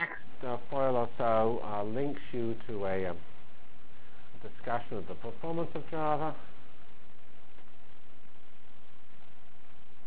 From Feb 5 Delivered Lecture for Course CPS616 -- Java as a Computional Science and Engineering Programming Language CPS616 spring 1997 -- Feb 5 1997.